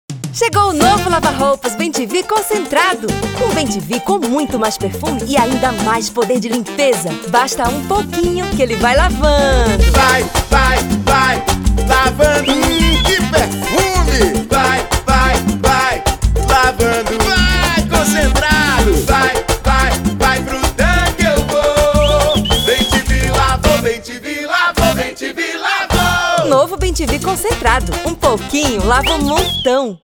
4.5 Rádio & Áudio